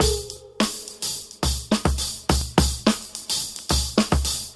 • 106 Bpm High Quality Drum Loop C Key.wav
Free drum groove - kick tuned to the C note. Loudest frequency: 4611Hz
106-bpm-high-quality-drum-loop-c-key-96A.wav